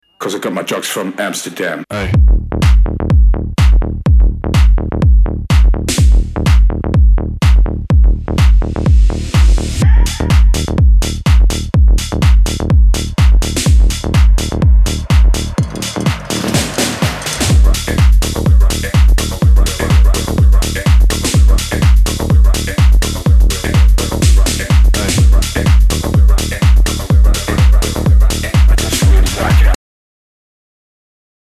мощные
Electronic
Tech House
Стиль: tech house